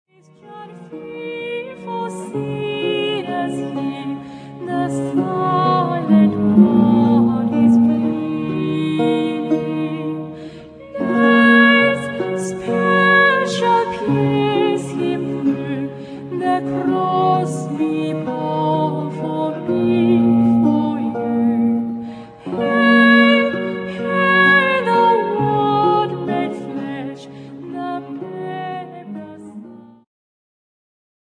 ジャンル Progressive
リュート
管楽器フューチュア
弦楽器系
古楽
癒し系
中世・ルネサンス・初期バロックの曲をブロークンコンソート形式で演奏。